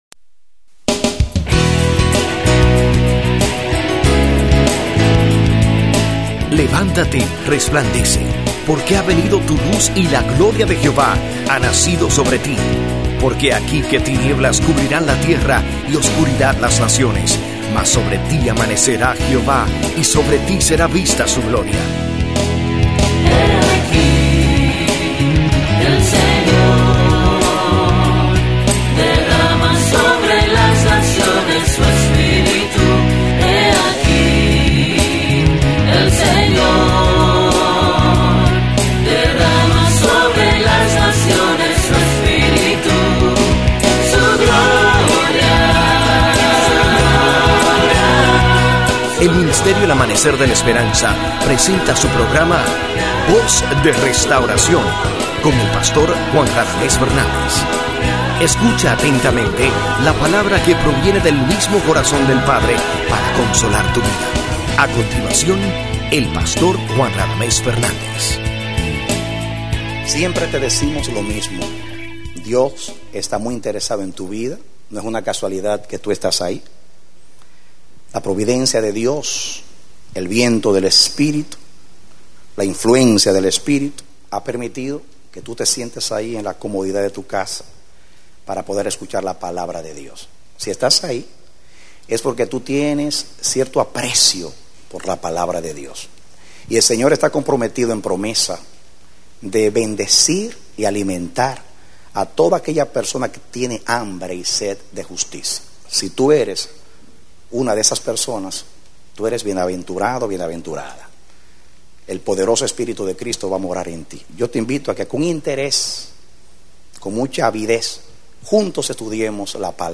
Mensaje: “A Dios lo que es de Dios”